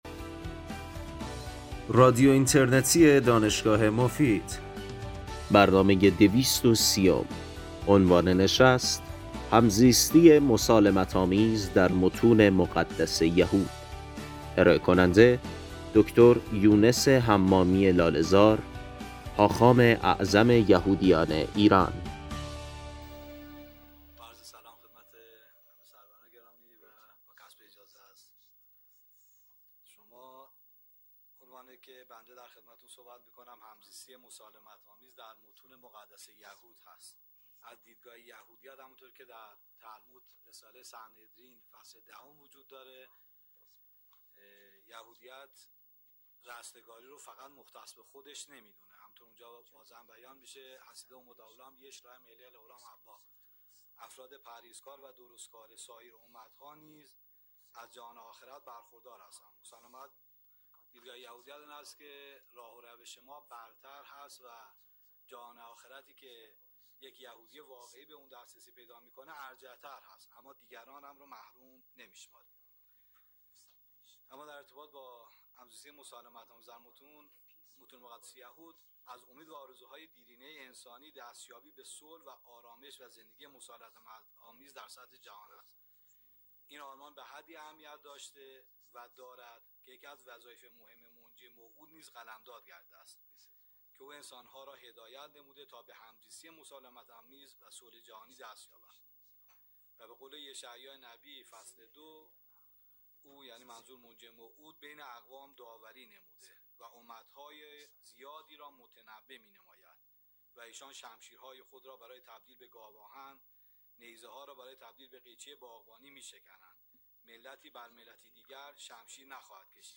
این سخنرانی در سال ۱۳۹۶ و در همایش بین المللی ادیان ابراهیمی و همزیستی مسالمت‌آمیز ایراد گردیده است.